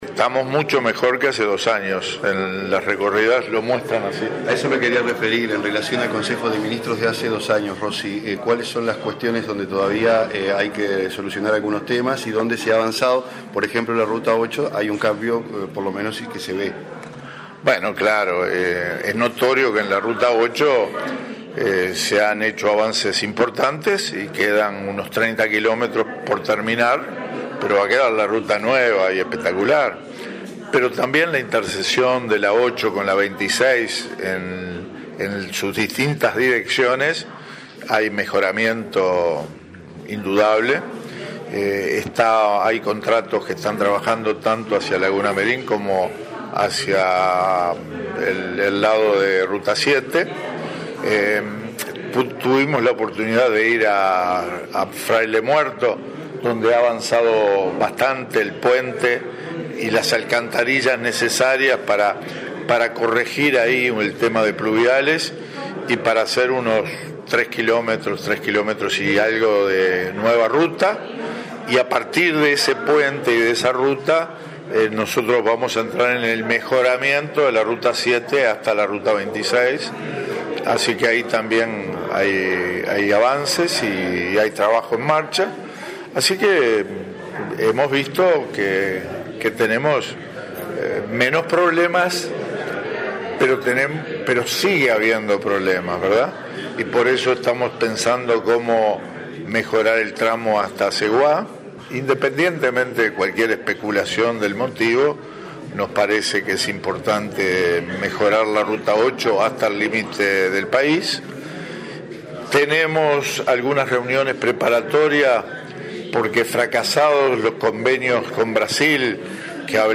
Quedan 30 kilómetros para finalizar las obras sobre la ruta 8 hasta la capital de Melo, Cerro Largo, luego de los cuales “quedará espectacular”, calificó el ministro de Transporte y Obras Públicas, Víctor Rossi, en la jornada previa al Consejo de Ministros abierto de este lunes. A estos trabajos, sumó avances en la intersección entre las rutas 8 y 26, la ruta 7 y el puente internacional sobre el río Yaguarón.